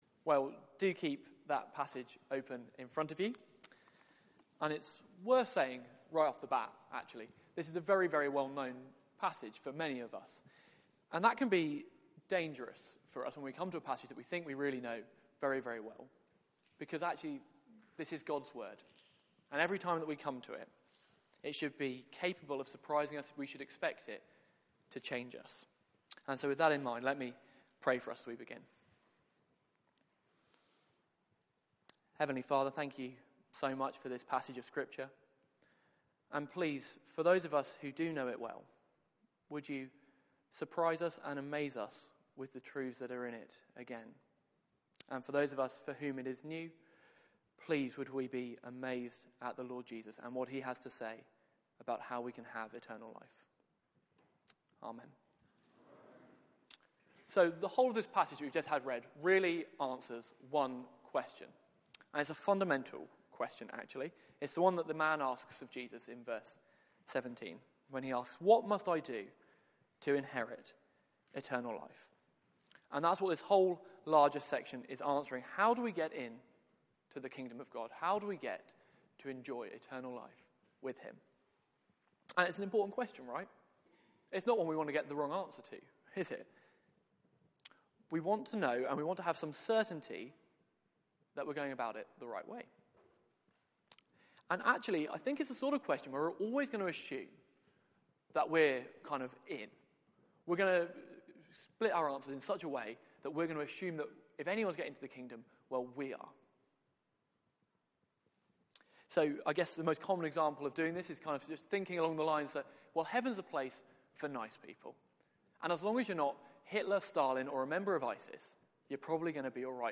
Media for 6:30pm Service on Sun 21st Feb 2016 18:30 Speaker
Mark 10:13-31 Series: Following Jesus Theme: Can we be good enough for God? Sermon Search the media library There are recordings here going back several years.